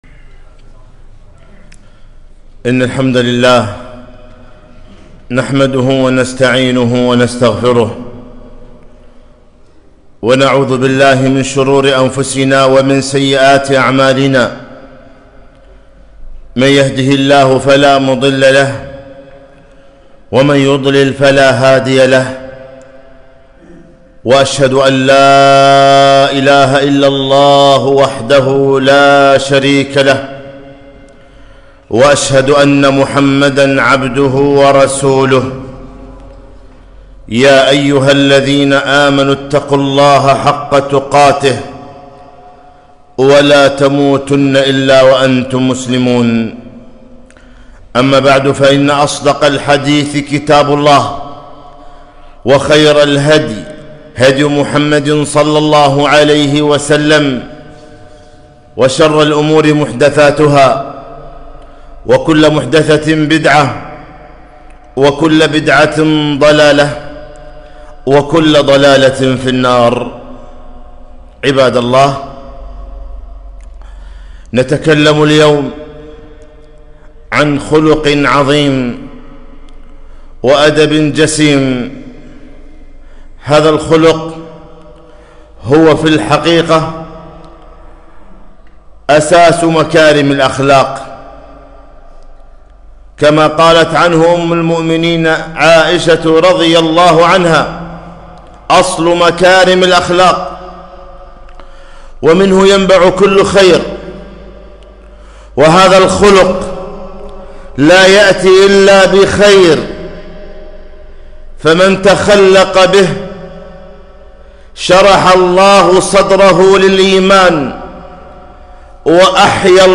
خطبة - الحياء لا يأتي إلا بخير